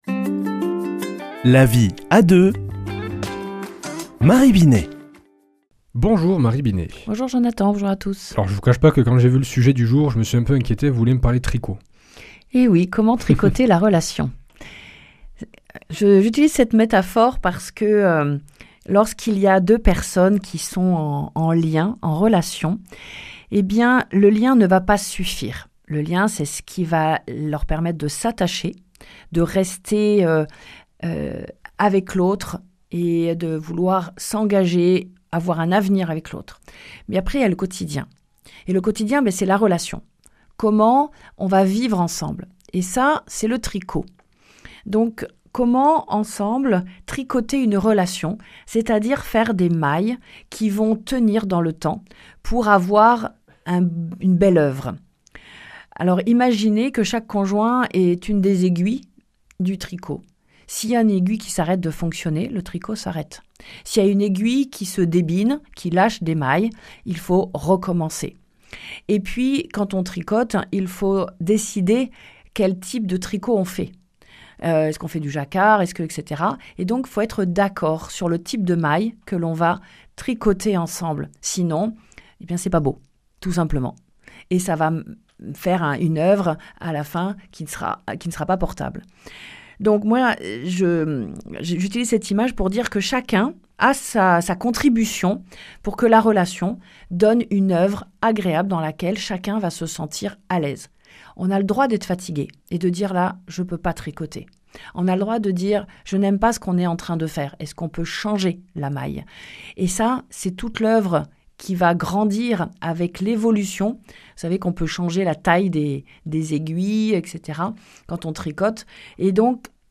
mardi 14 octobre 2025 Chronique La vie à deux Durée 4 min
Une émission présentée par